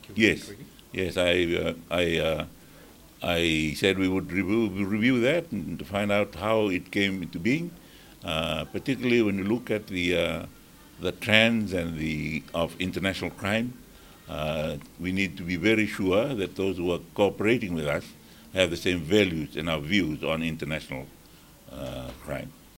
In a recent interview with FBC News, Rabuka clarified that Fiji is not seeking to distance itself from China and that their relationship is still based on the One China Policy.